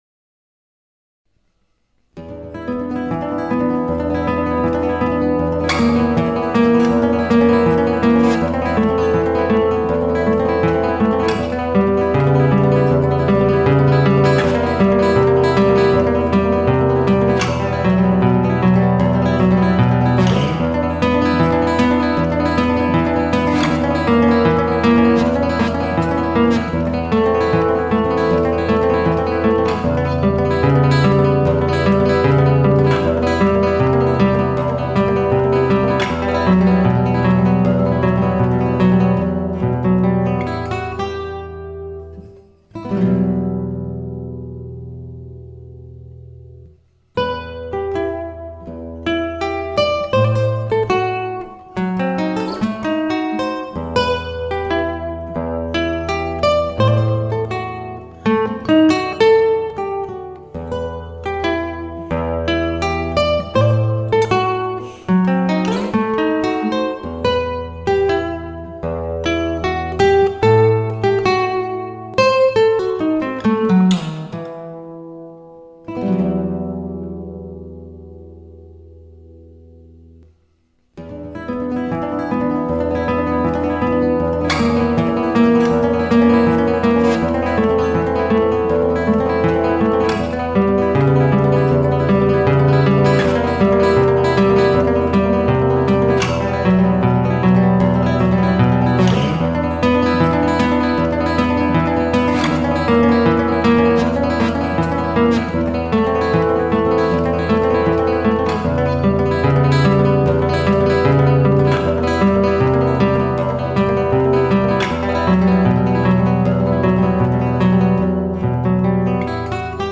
Gitarre, akustische Gitarre
Klassischer Stil